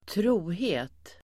Uttal: [²tr'o:he:t]